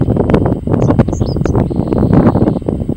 Correndera Pipit (Anthus correndera)
Location or protected area: Reserva Natural y Dique La Angostura
Condition: Wild
Certainty: Recorded vocal